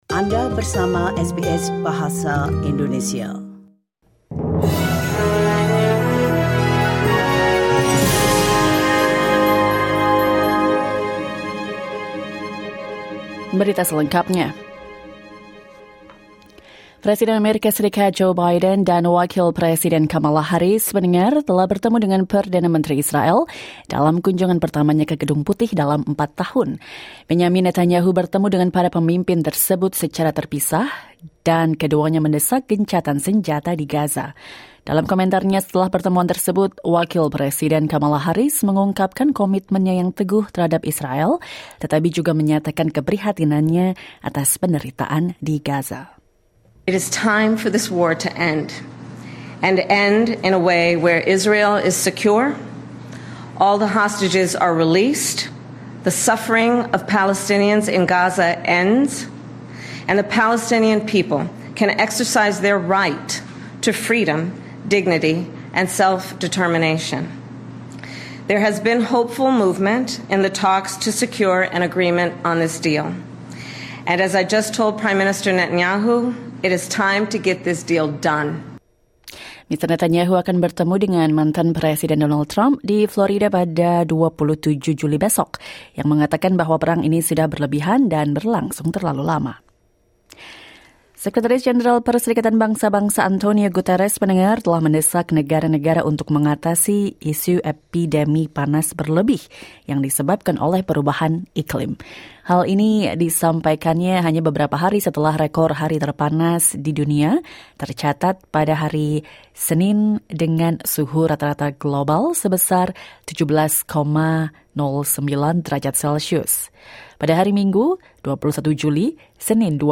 SBS Audio news in Indonesian - 26 July 2024